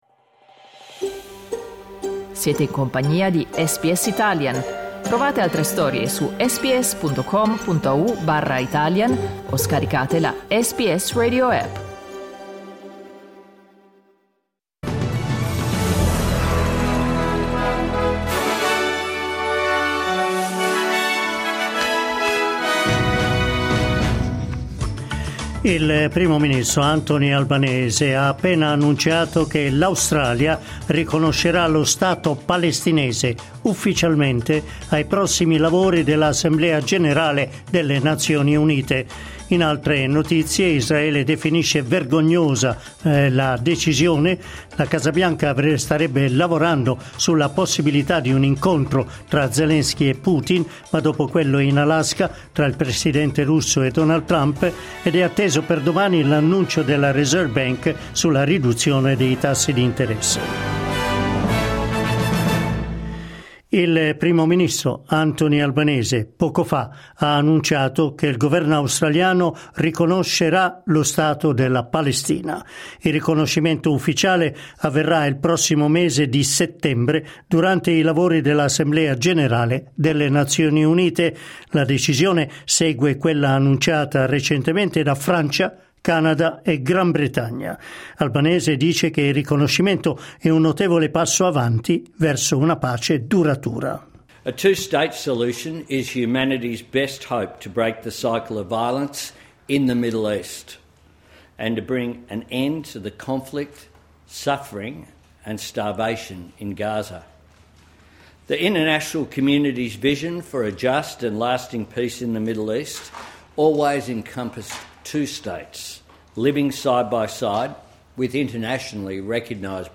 News flash lunedì 11 agosto 2025